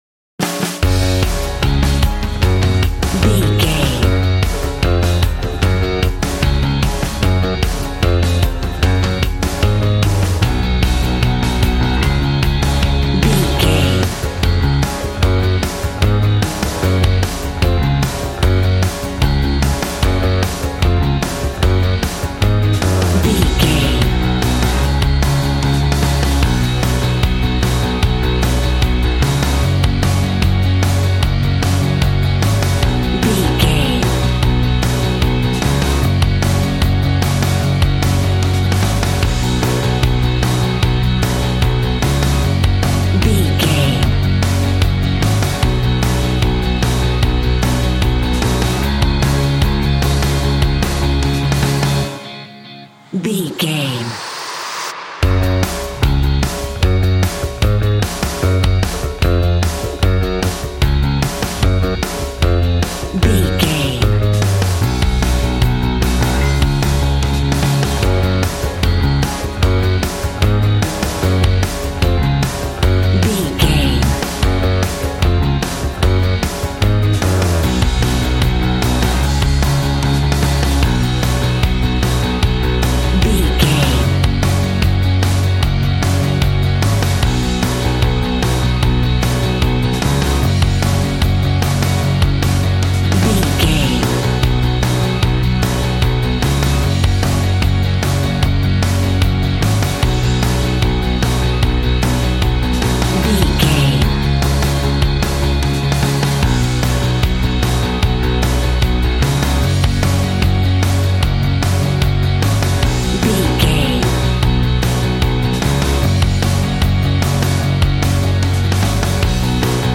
Ionian/Major
G♭
groovy
happy
electric guitar
bass guitar
drums
piano
organ